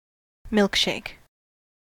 Ääntäminen
Synonyymit frappe thickshake shake Ääntäminen US Tuntematon aksentti: IPA : /mɪlk ʃeɪk/ Haettu sana löytyi näillä lähdekielillä: englanti Käännös Substantiivit 1.